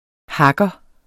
Udtale [ ˈhɑgʌ ]